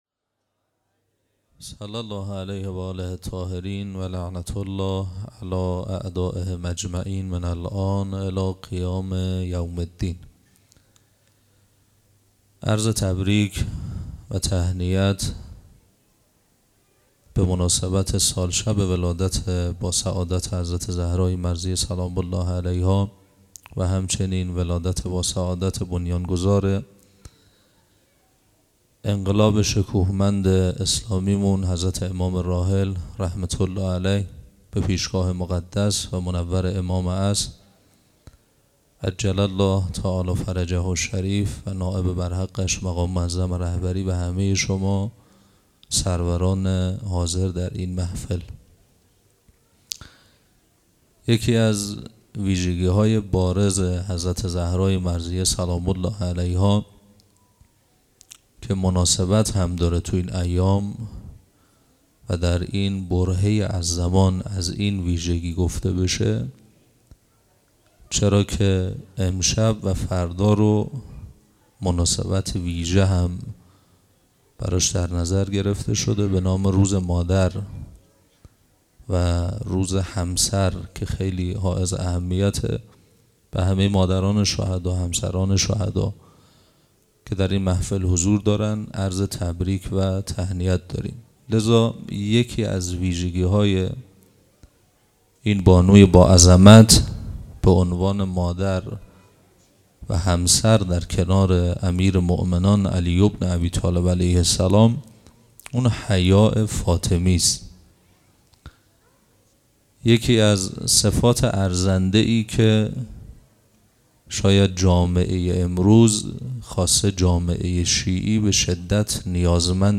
صوت مراسم ولادت حضرت زهرا(س)